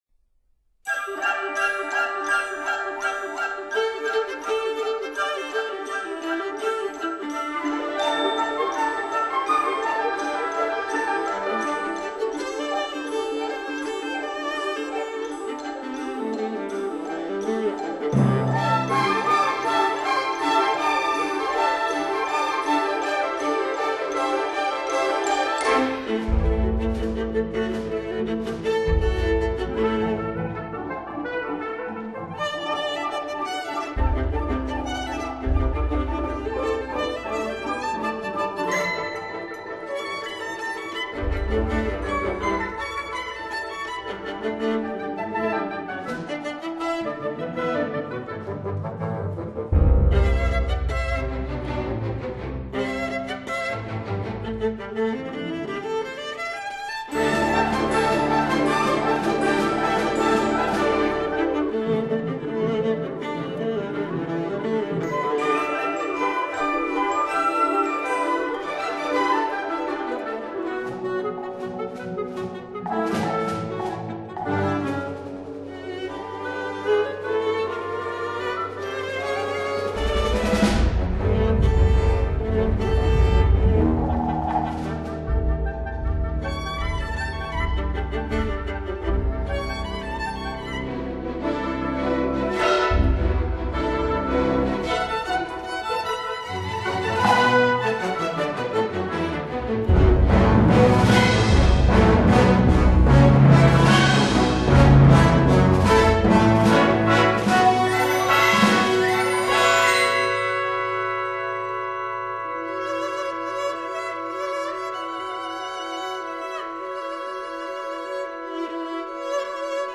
viola